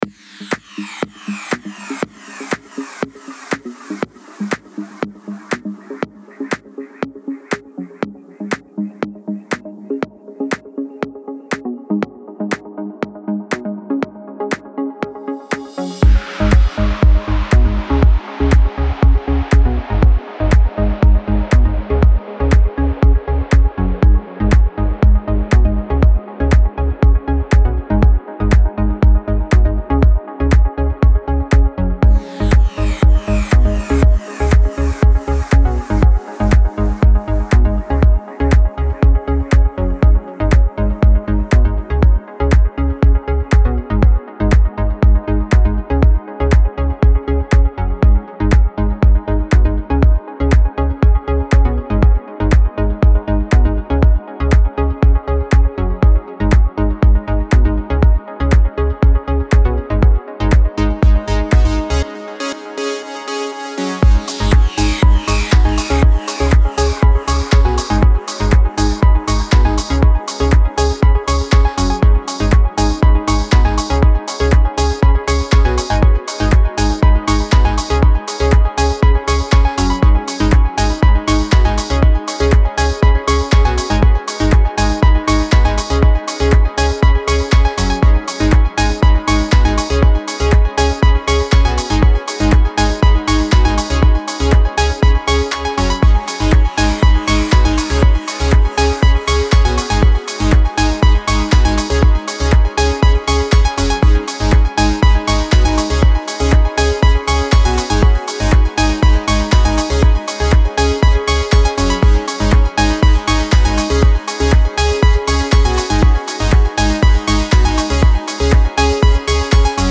dance-background.mp3